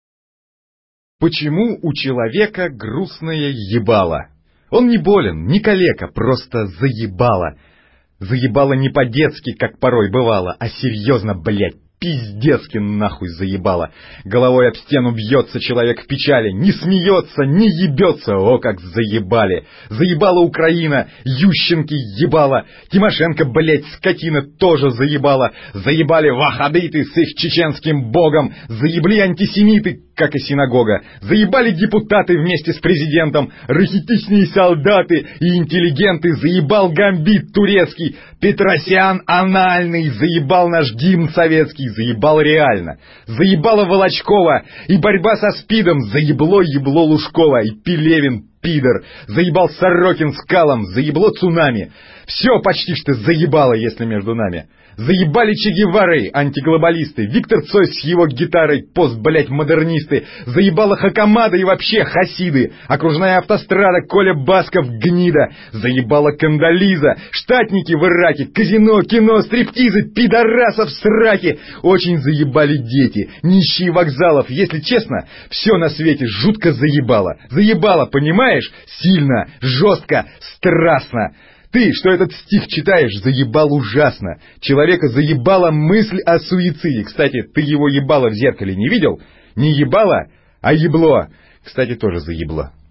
Аудио прикол!
Внимание! Файл содержит нецензурную брань,детям качать ЗАПРЕЩАЕТСЯ!